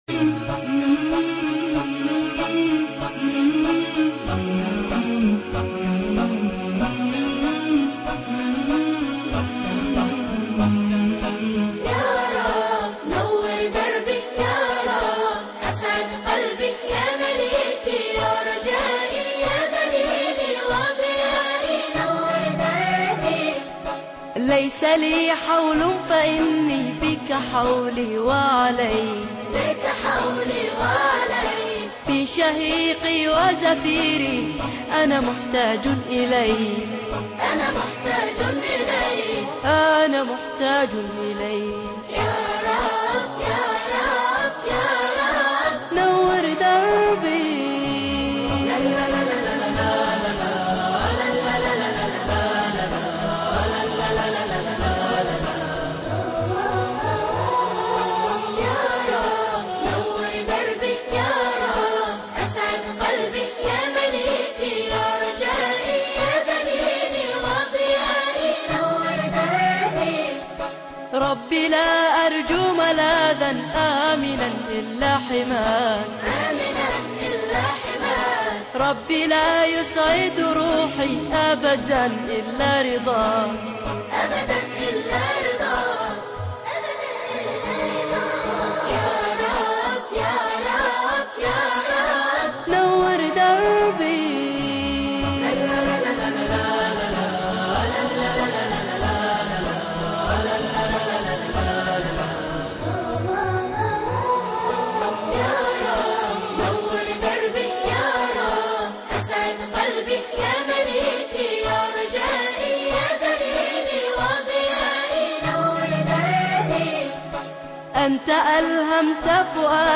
ألحانه وايد روعة ( اموت فيها )
المنشد صوته حلو